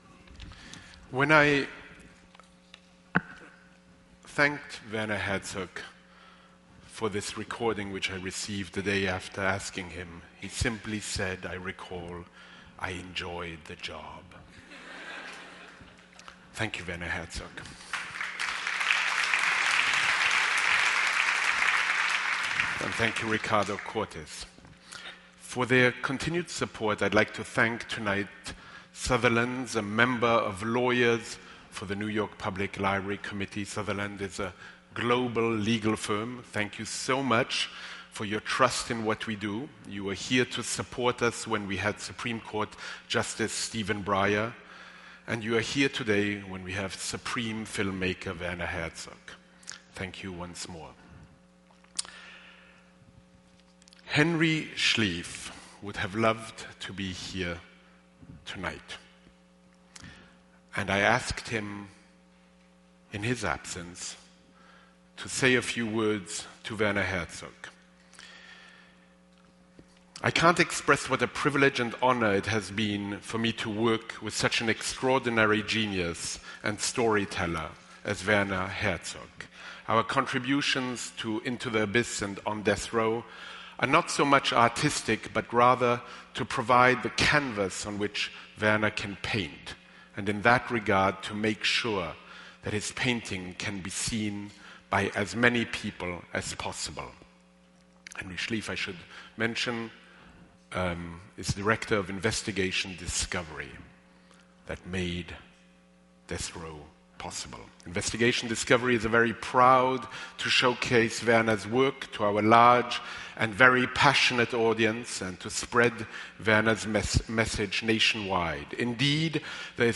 WERNER HERZOG: DEATH ROW & Other Journeys, a conversation with Paul Holdengräber